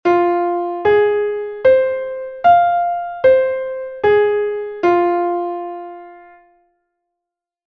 arpexio_fa_menor.mp3